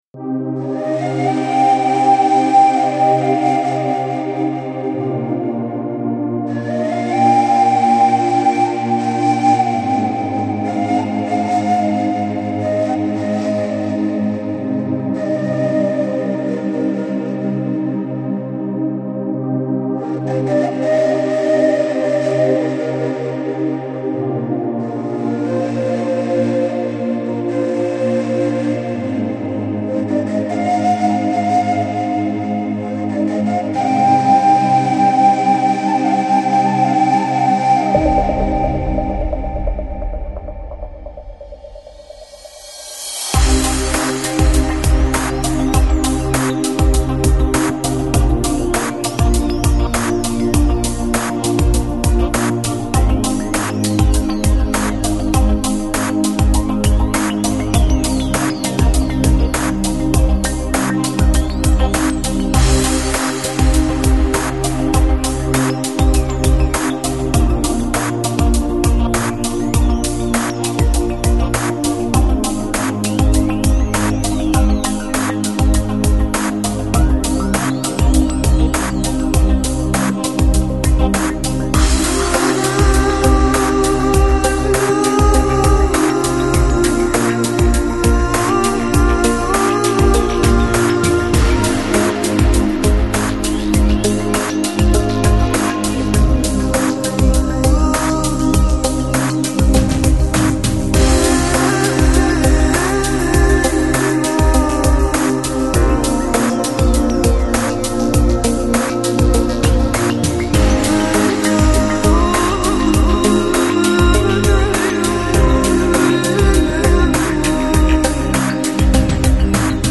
Жанр: New Age, Electronic, Ambient